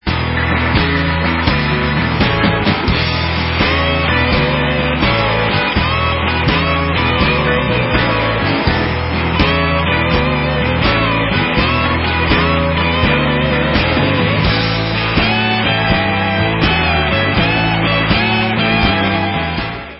New studio album